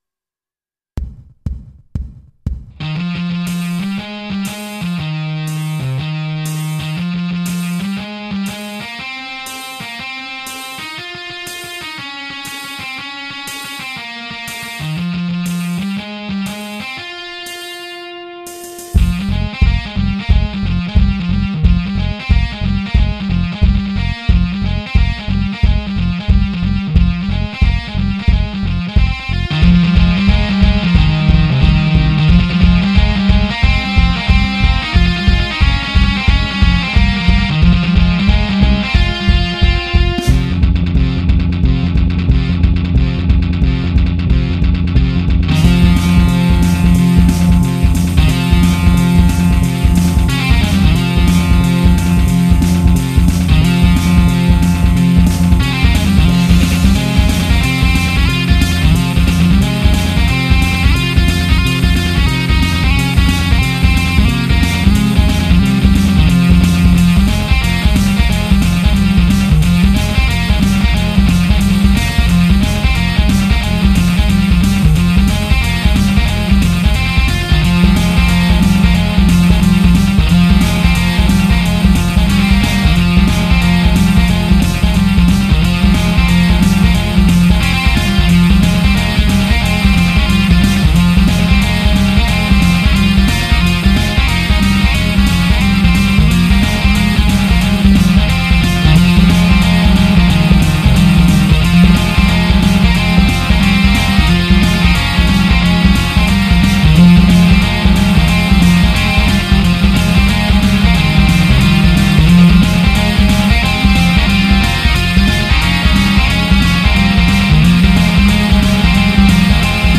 Веселая песенка про муравьев.